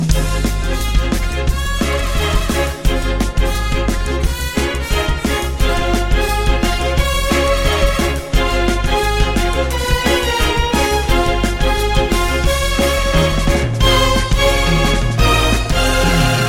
Nhạc Chuông Nhạc Phim